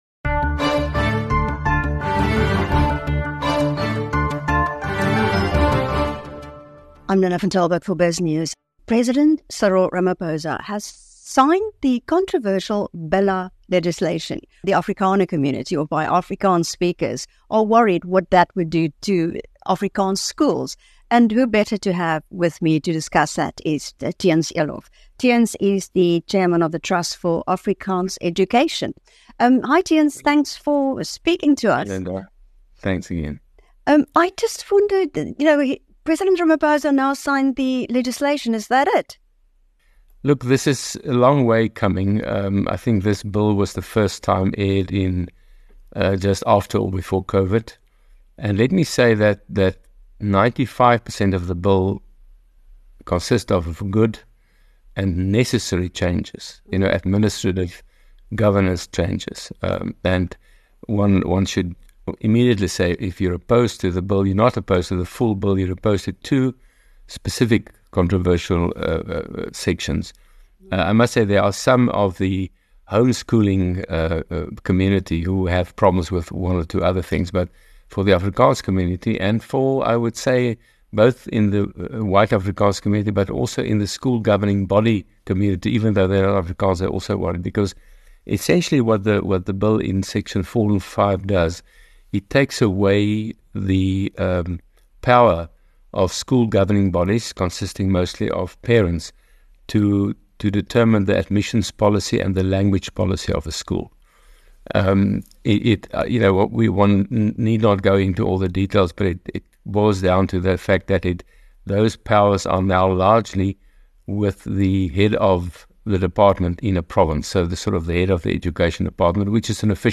President Cyril Ramaphosa has signed the contentious Basic Education Laws Amendment (Bela Bill) into law, which proposes various amendments, including giving provincial Heads of Departments greater control over schools’ language and admission policies. The Bill removes some decision-making powers from school governing bodies and is seen as a direct attack on schools that teach in Afrikaans. In an interview with Biznews